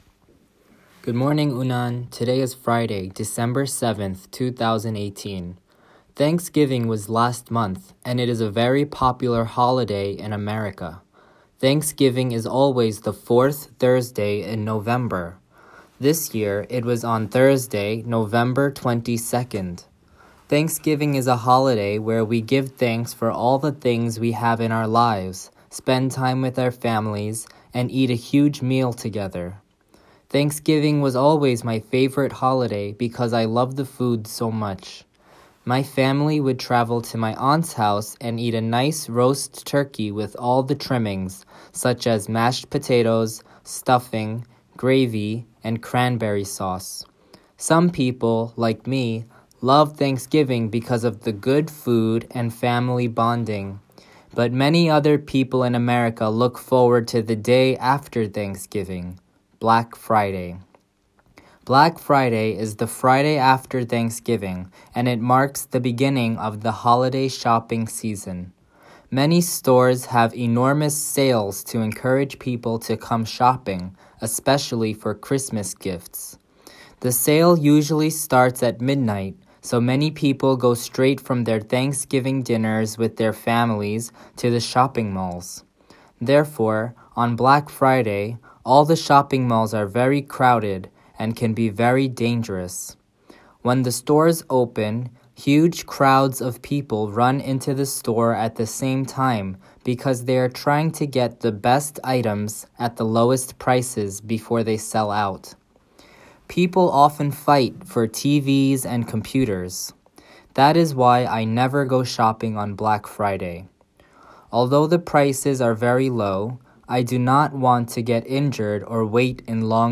Morning Speech - 栃木県立宇都宮南高等学校